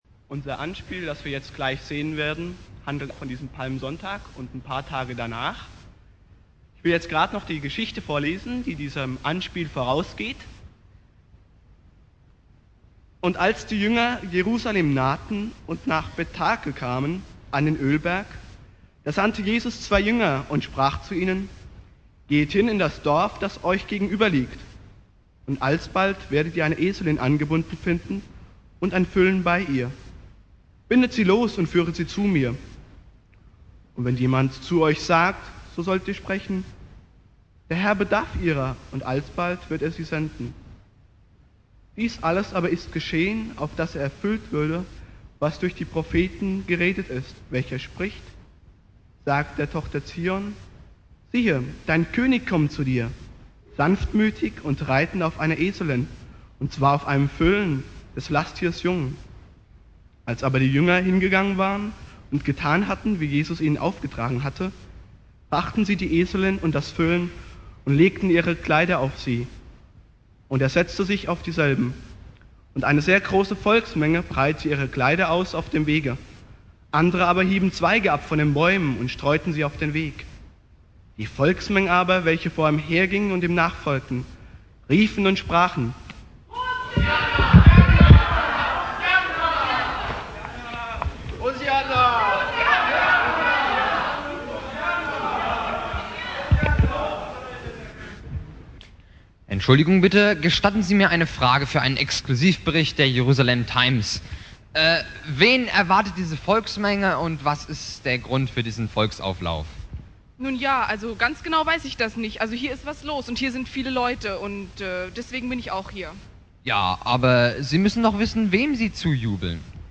Predigt
(Jugendgottesdienst)